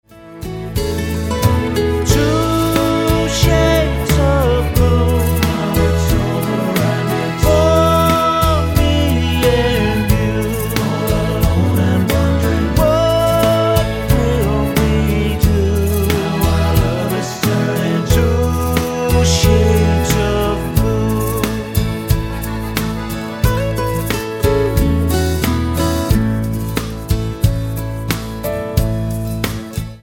--> MP3 Demo abspielen...
Tonart:A-Bb mit Chor